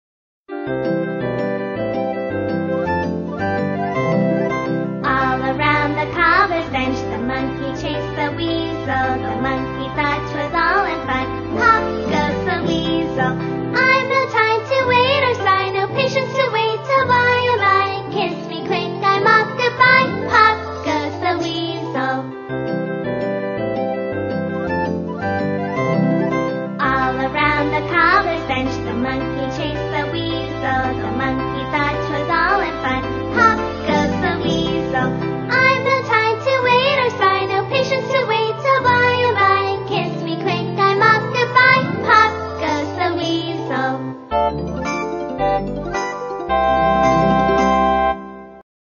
在线英语听力室英语儿歌274首 第168期:Pop!Goes the Weasel的听力文件下载,收录了274首发音地道纯正，音乐节奏活泼动人的英文儿歌，从小培养对英语的爱好，为以后萌娃学习更多的英语知识，打下坚实的基础。